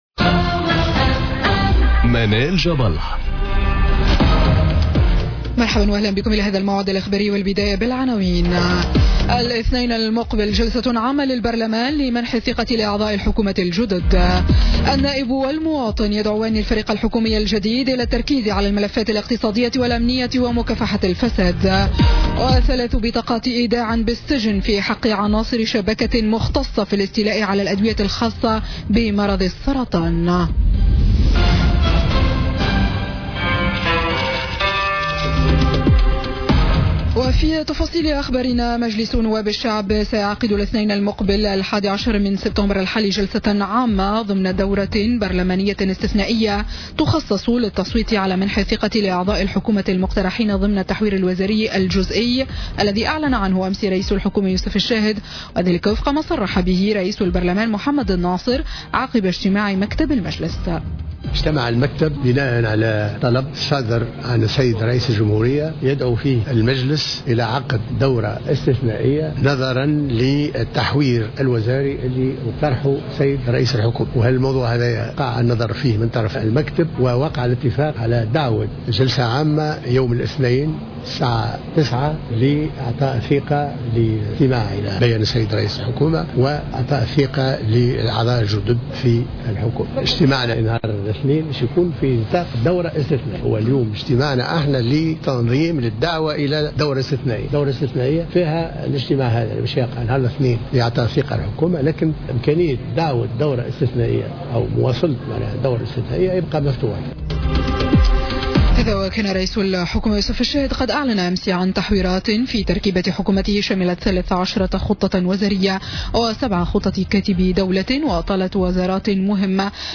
Journal Info 19h00 du Jeudi 07 Septembre 2017